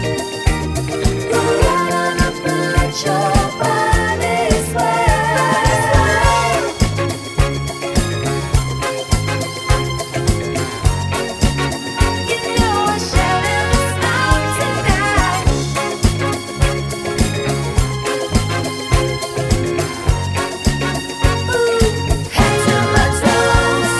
no bass Pop (1980s) 3:15 Buy £1.50